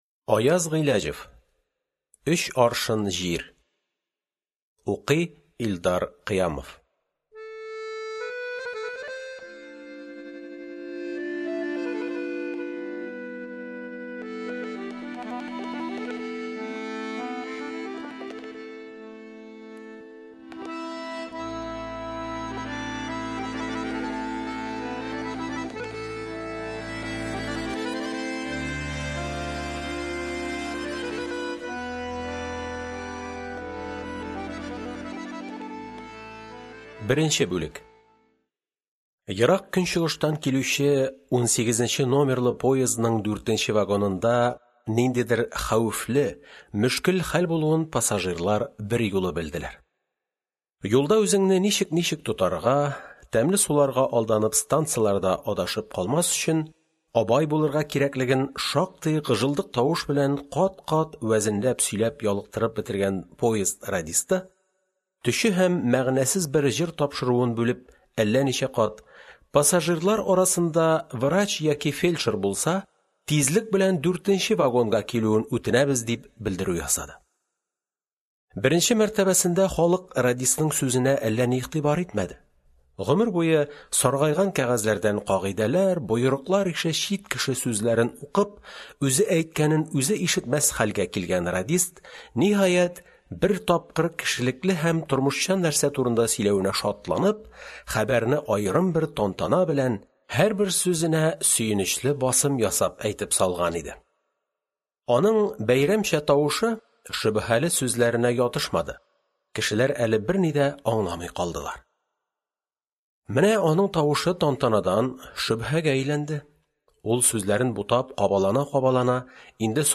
Аудиокнига Әтәч менгән читәнгә | Библиотека аудиокниг